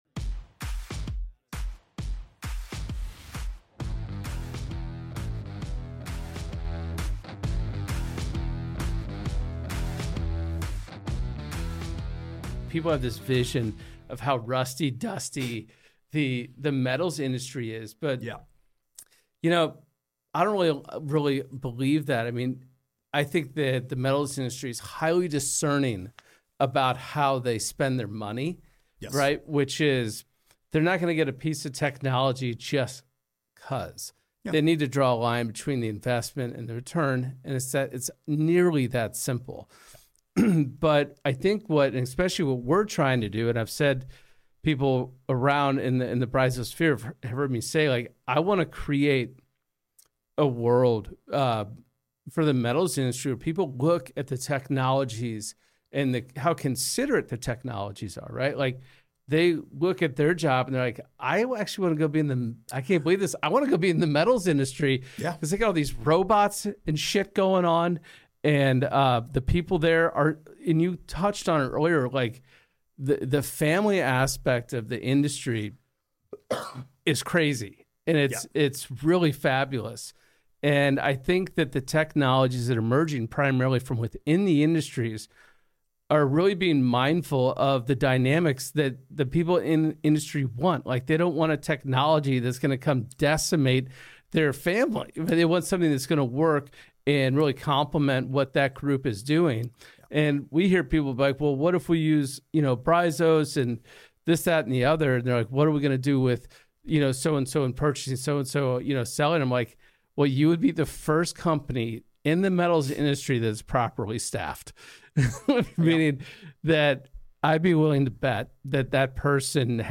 This episode pulls together our favorite mic-drop moments, unexpected tangents, and conversations that stuck with us.